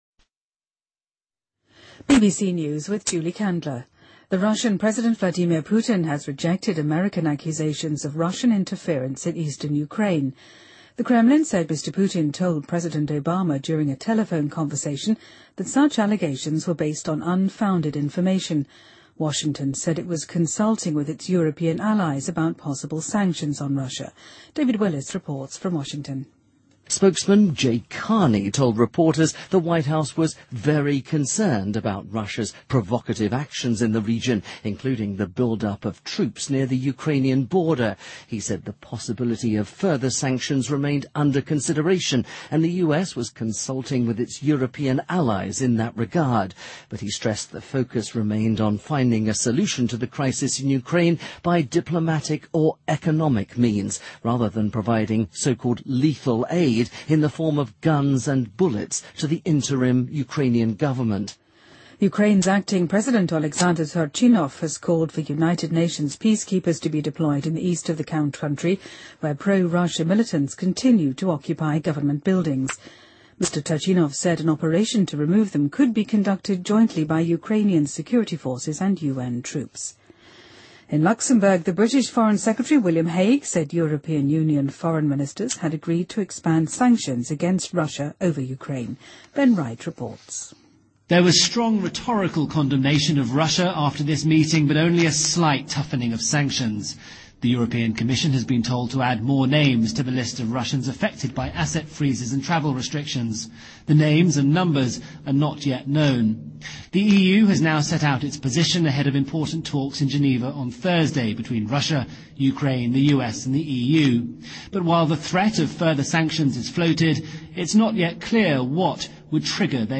BBC news,华盛顿邮报和英国卫报美国版都获得新闻界令人垂涎的普利策奖